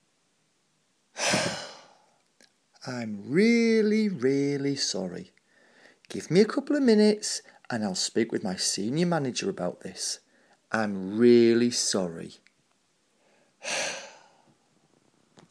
I'm really sorry (insincere)